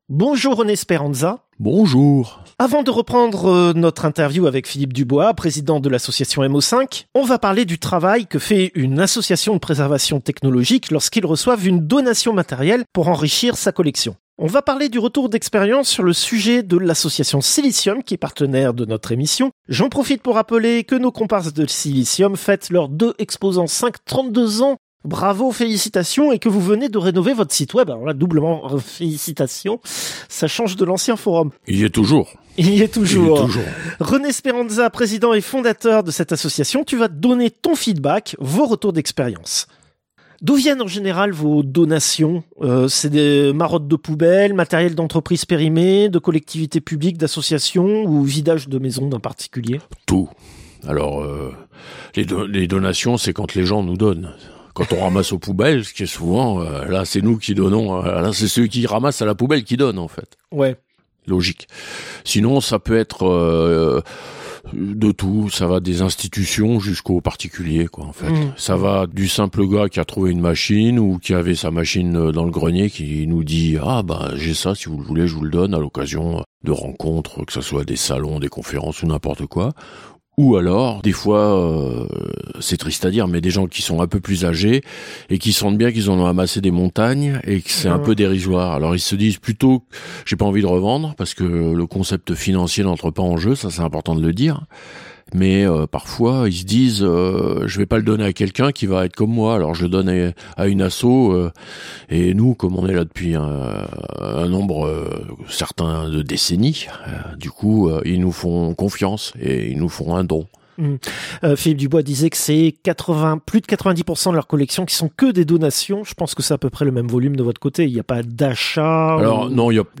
[La suite de la chronique est en mode interview et n'a pas été transcrite, voici les questions posées :] D'où viennent en général vos donations ?